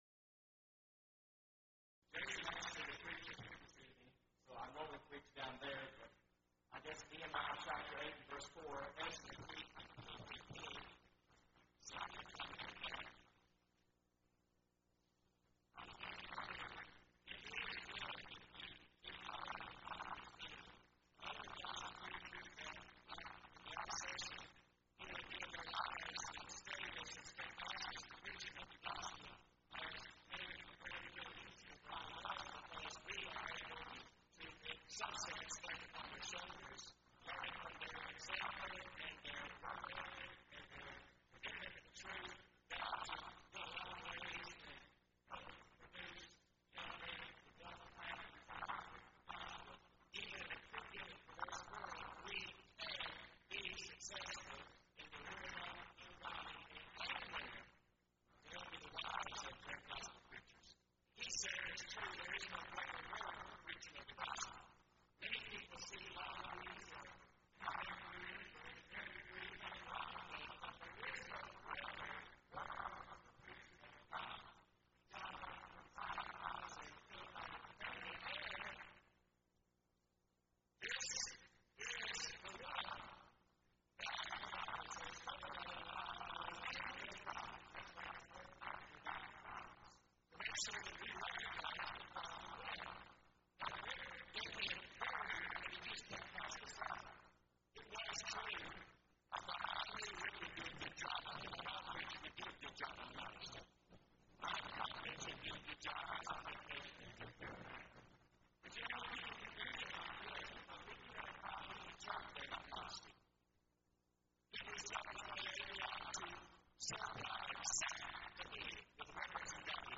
Event: 2nd Annual Young Men's Development Conference
lecture